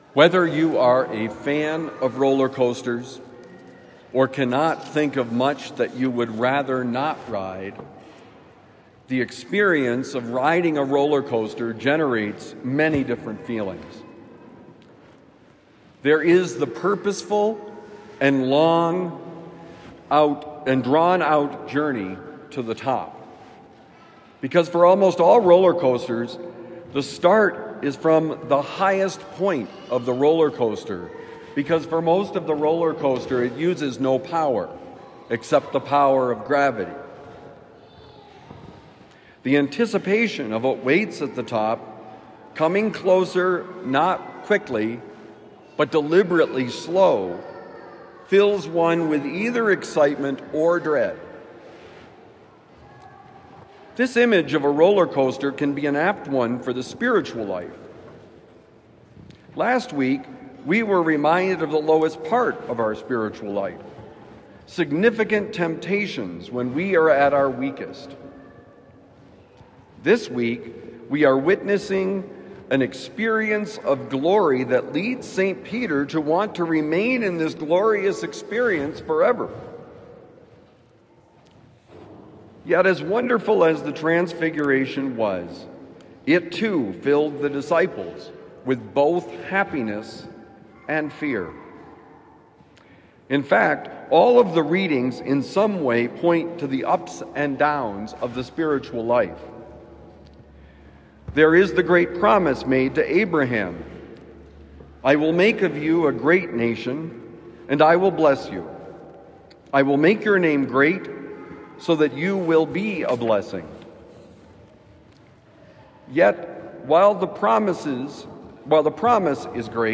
Roller Coaster: Homily for Sunday, March 1, 2026